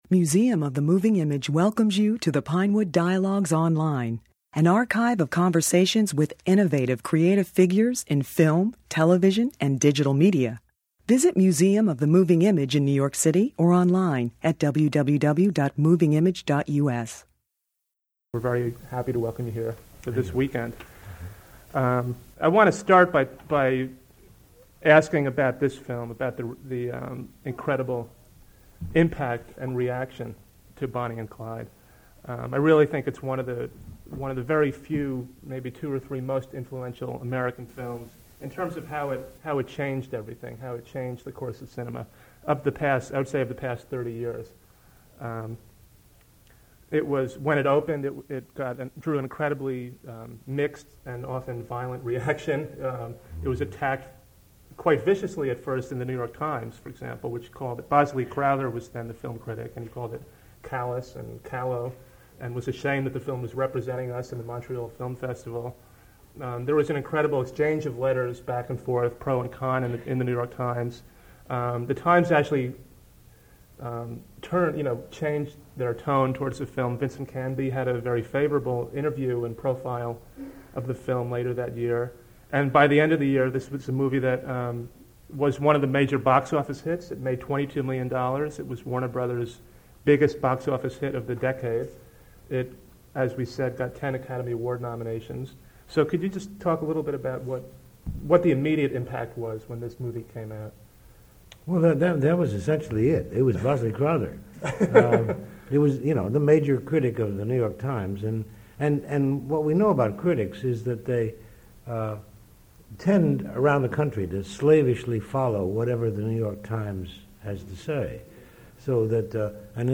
During the Museum of the Moving Image retrospective American Outsiders: The Cinema of Arthur Penn, the director discussed the critical controversy surrounding the film's release, and the remarkable collaboration that included Warren Beatty as producer and star, and screenwriter Robert Benton.